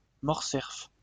Mortcerf (French pronunciation: [mɔʁsɛʁf]